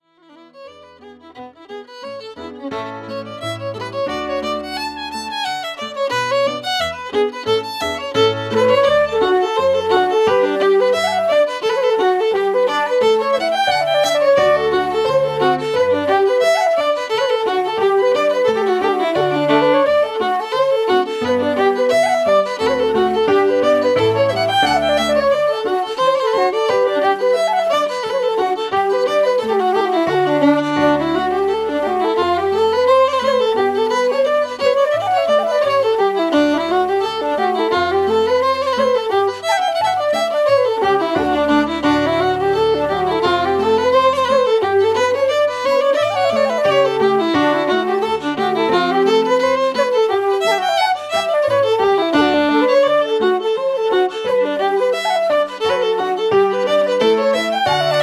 Hornpipes 04:34